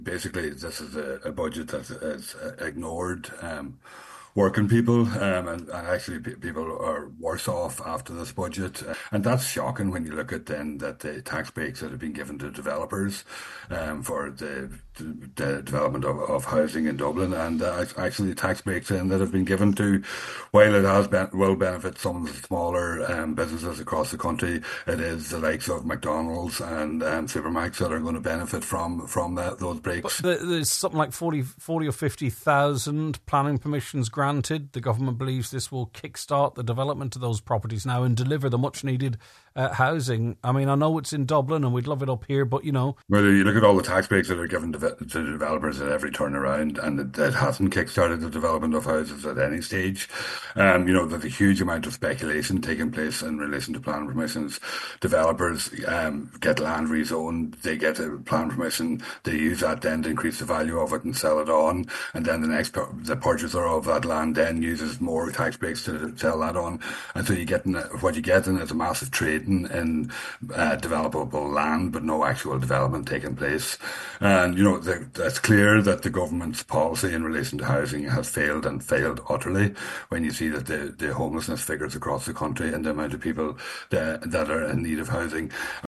on this morning’s Nine til Noon Show, former Independent Deputy Thomas Pringle says the Budget has prioritised property developers: